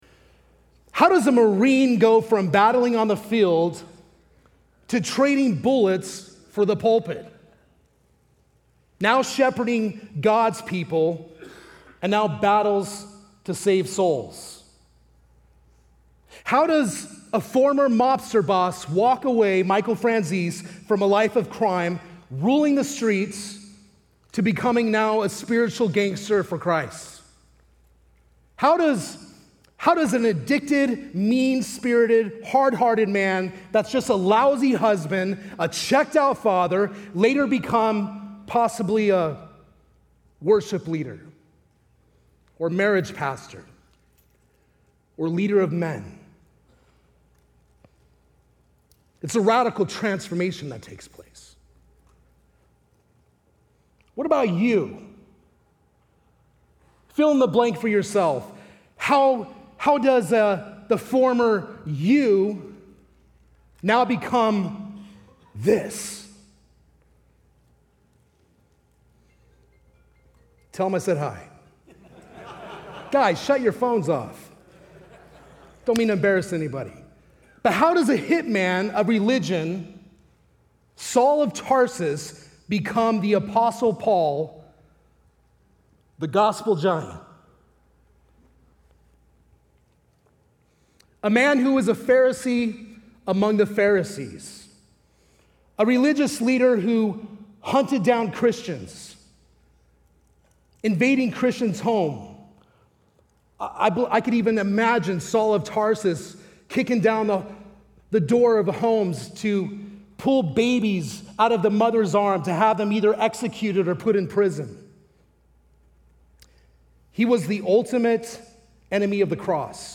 at the Men's Conference in Tucson, Arizona in 2025.